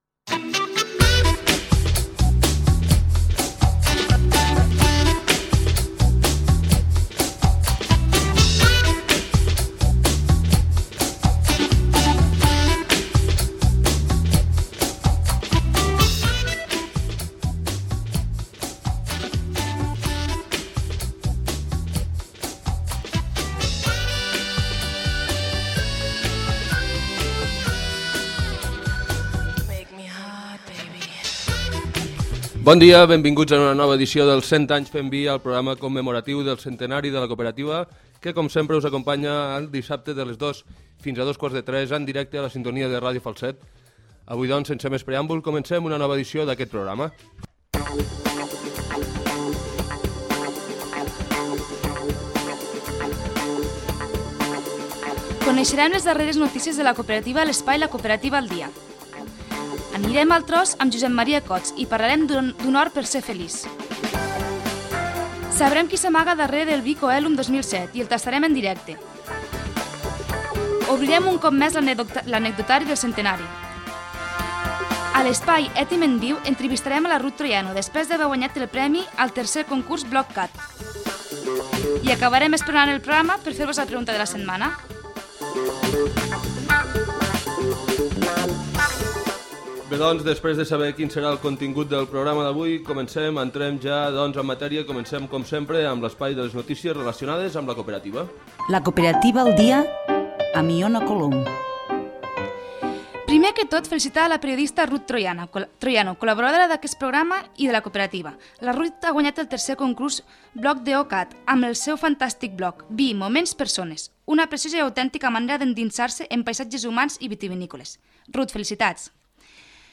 f91d526e15ec1b7807ecf63bdc44f45fe327fdde.mp3 Títol Ràdio Falset Emissora Ràdio Falset Titularitat Pública municipal Nom programa 100 anys fent vi Descripció Inici del programa, sumari dels continguts, la cooperativa al dia, anem al tros, darrere d'un vi. Gènere radiofònic Divulgació